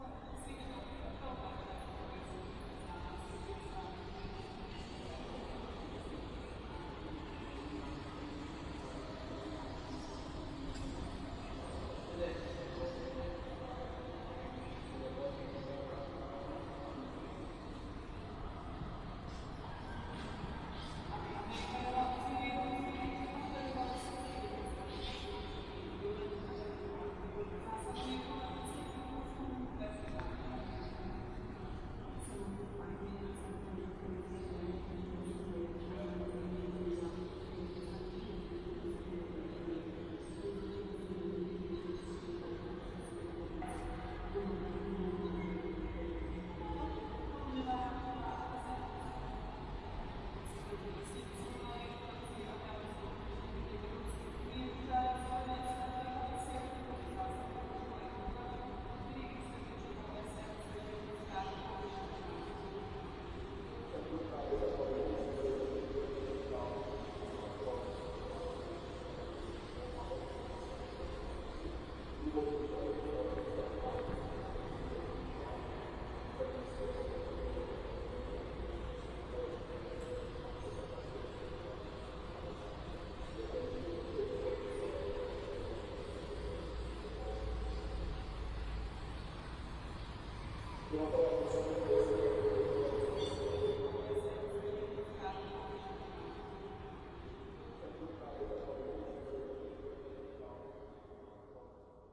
城市广播
描述：星期天中午在小城市，电视播放距离。 / ZOOM H2N
Tag: 科幻 语音 远处 气氛 环境 噪声 无线电 无人驾驶飞机 广播 回声 电视 城市 现场记录 音景 大气 城市